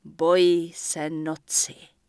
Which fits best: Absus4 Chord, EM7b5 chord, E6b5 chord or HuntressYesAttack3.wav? HuntressYesAttack3.wav